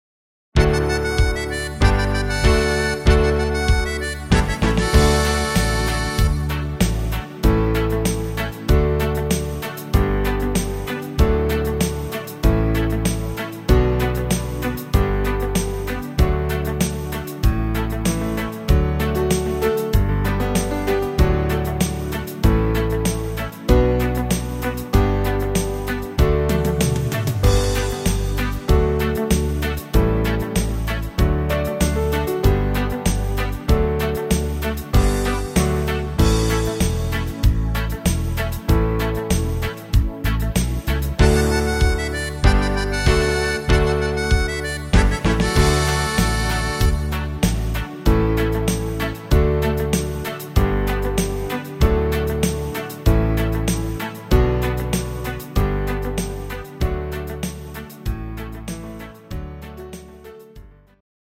Kurzmedley